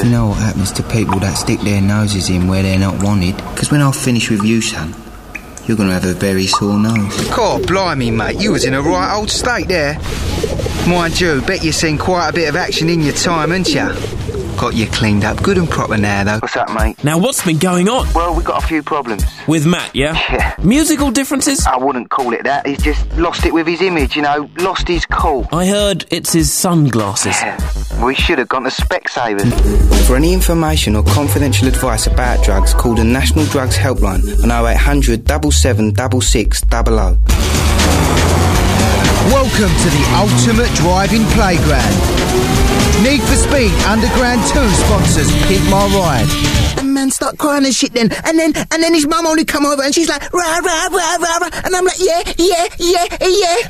Commercial Showreel
Cockney London
Character, Cheeky, Lad